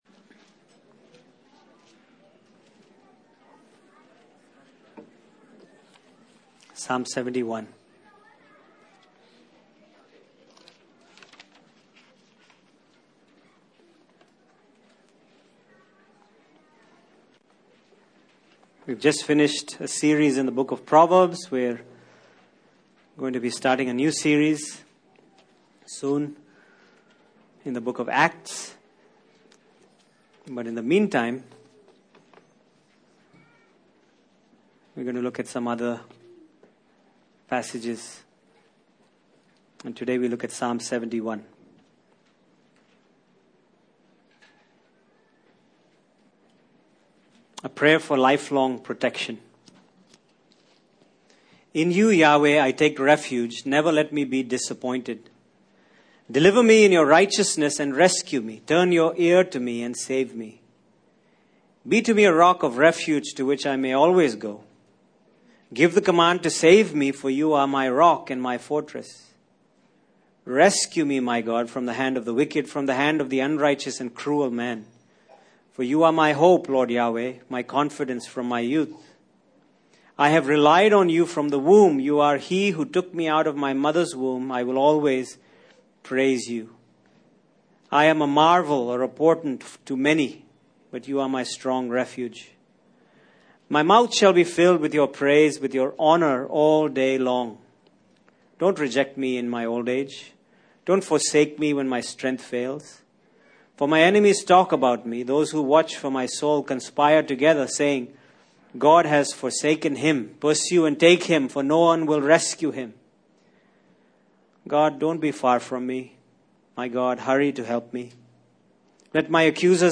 Passage: Psalm 71 Service Type: Sunday Morning